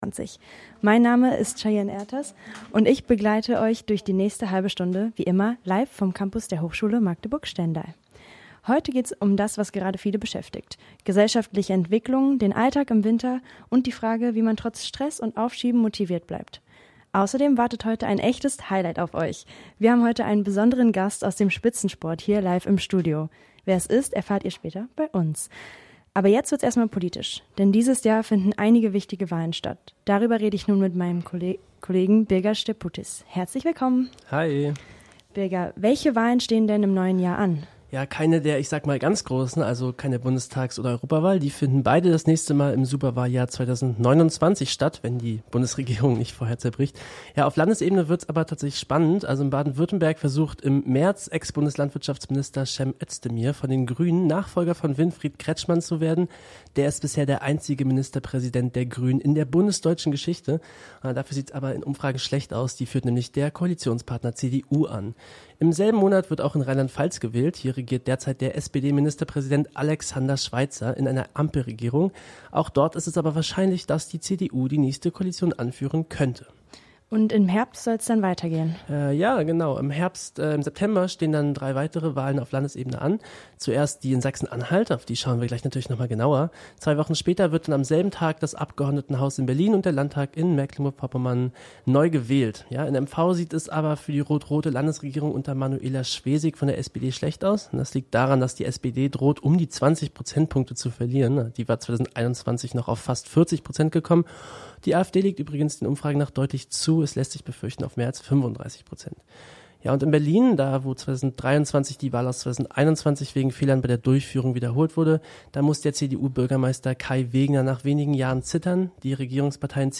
live im Studio